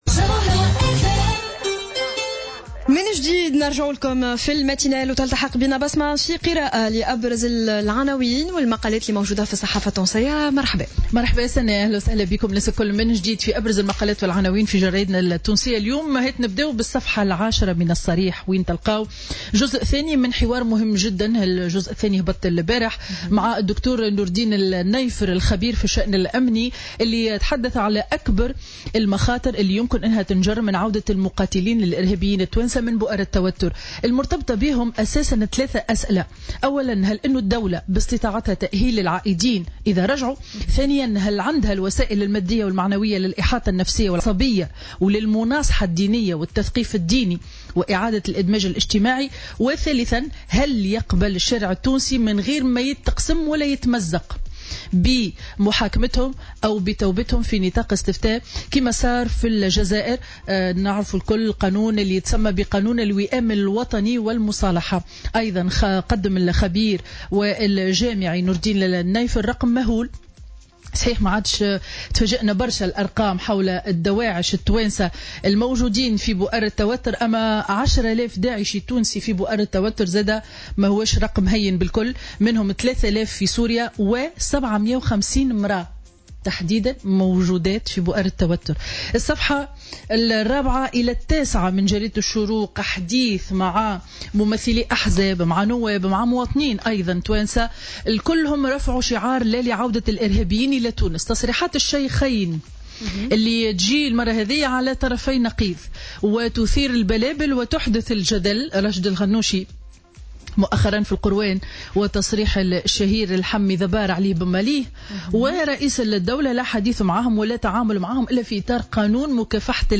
Revue de presse du mercredi 28 décembre 2016